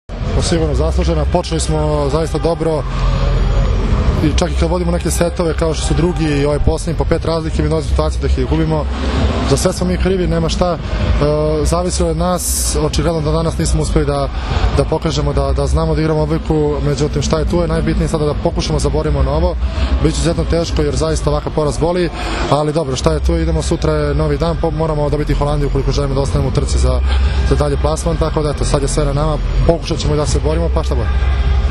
IZJAVA ALEKSANDRA ATANASIJEVIĆA